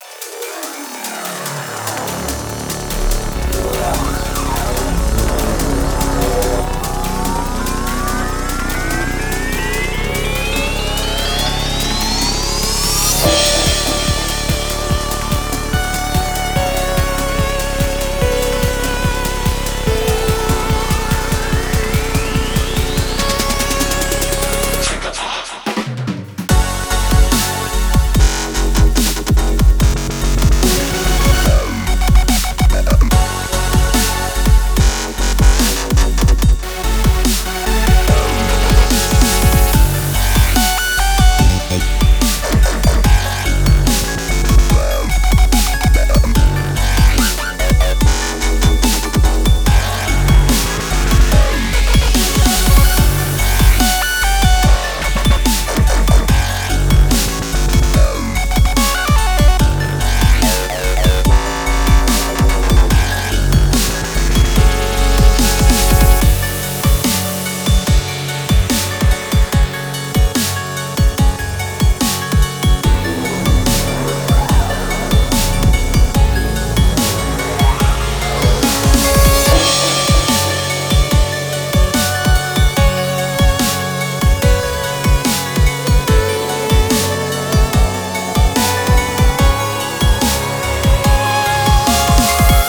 ◆ジャンル：Melodic Dubstep/メロディックダブステップ
-10LUFSくらいでマスタリングしております。